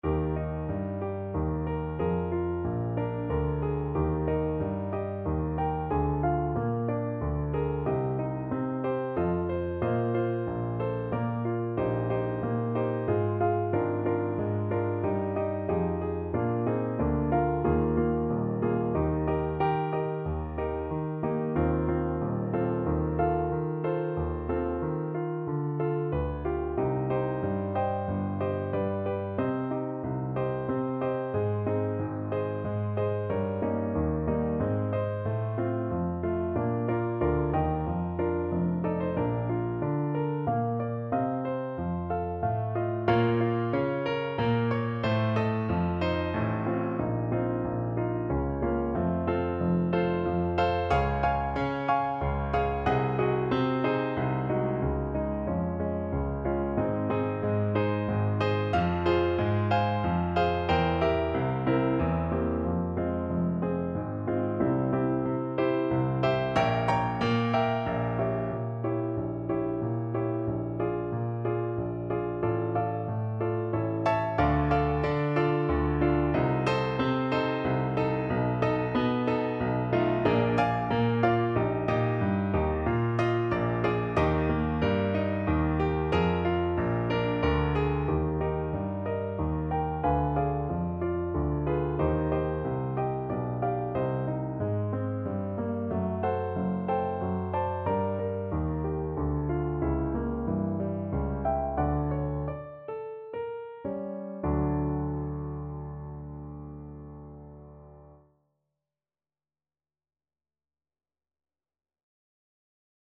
~ = 92 Larghetto